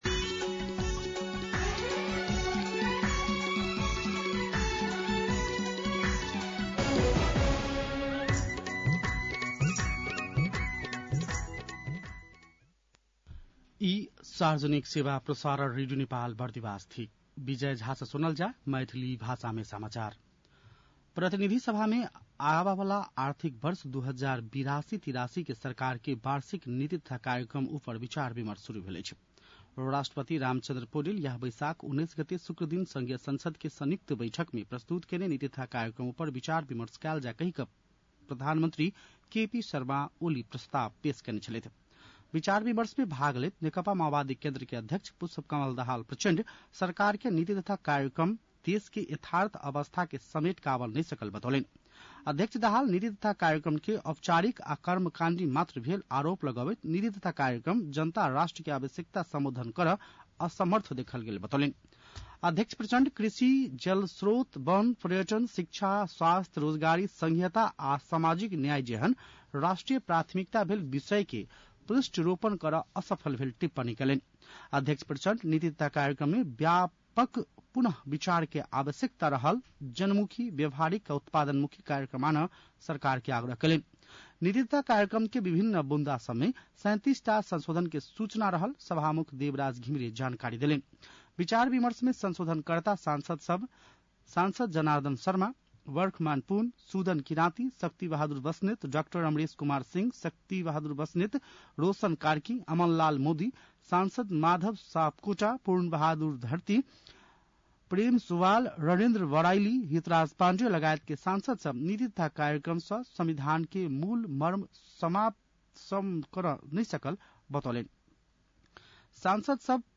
मैथिली भाषामा समाचार : २२ वैशाख , २०८२
6.-pm-maithali-news-1.mp3